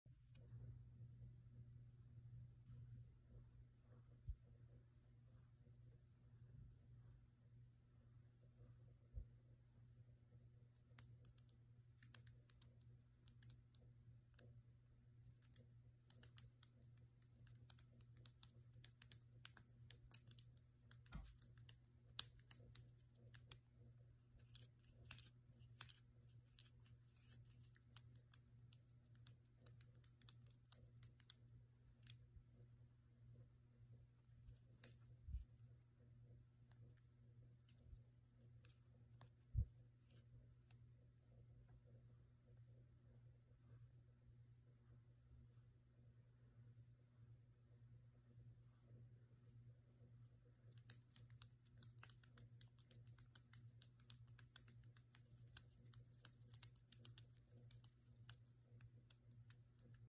no talking asmr hand movements and nail sounds